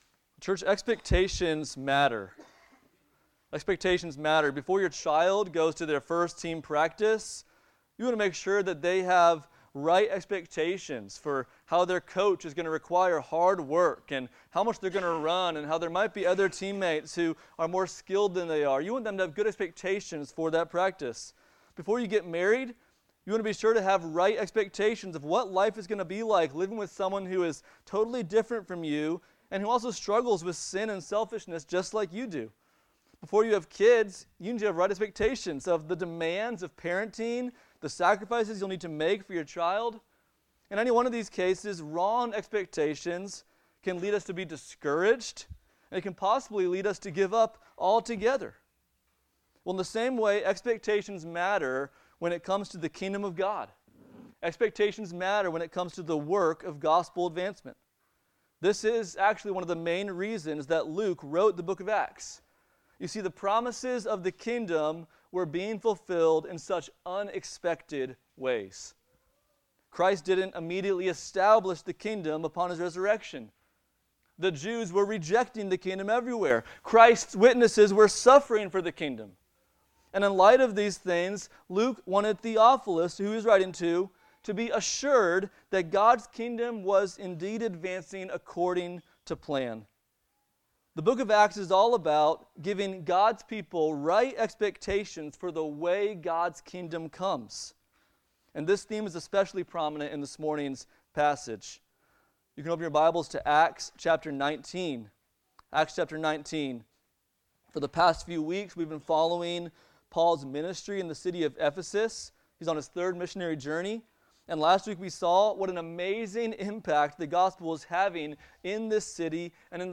The Saving Acts of God in Christ Passage: Acts 19:21-41 Service Type: Sunday Morning « But Who Are You?